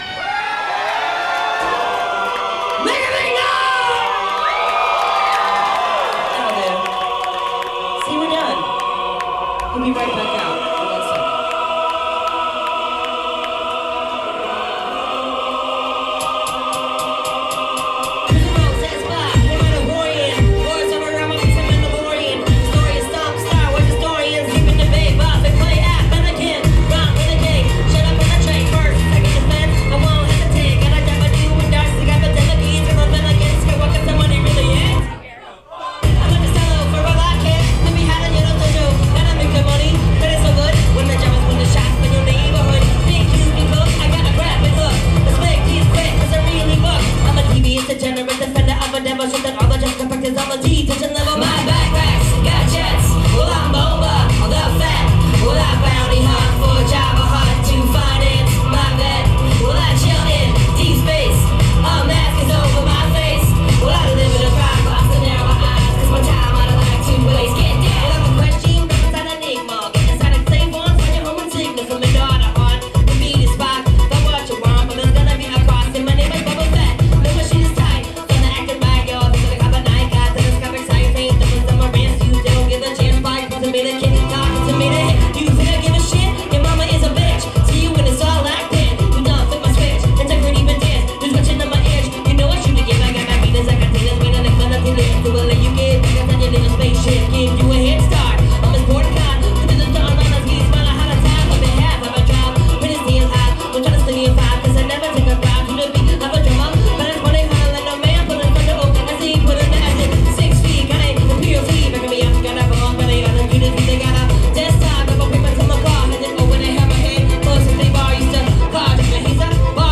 raps about a guy named Boba Fett.